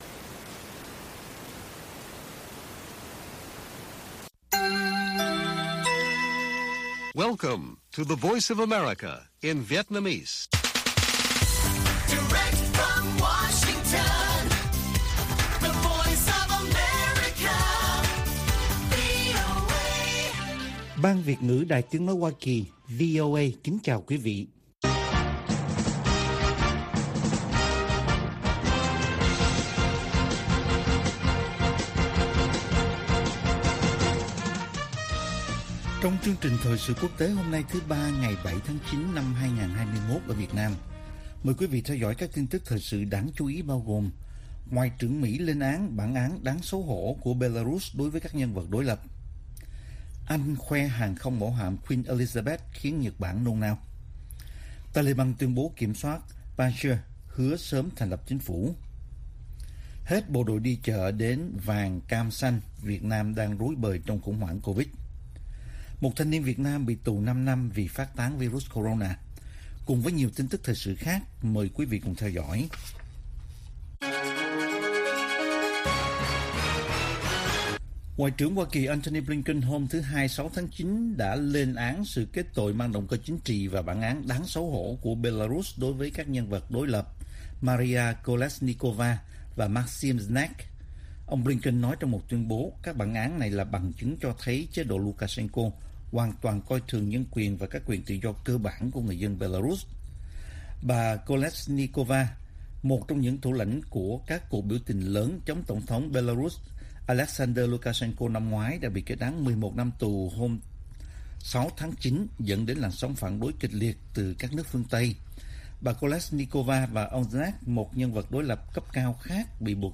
Bản tin VOA ngày 7/9/2021